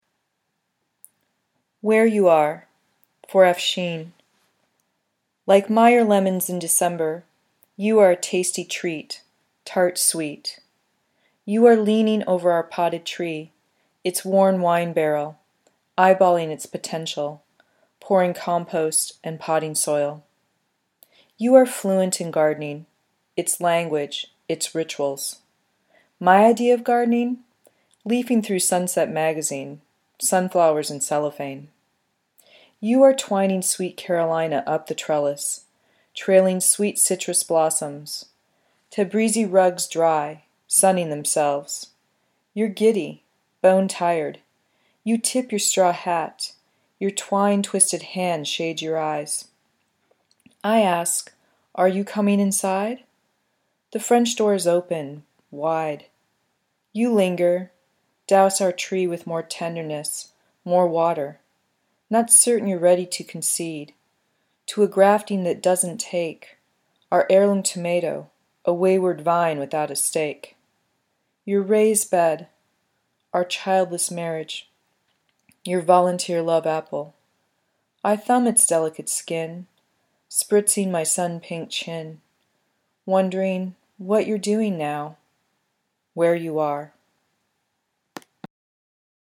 A selection of poems